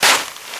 High Quality Footsteps
STEPS Sand, Walk 26.wav